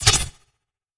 Whoosh Blade 002.wav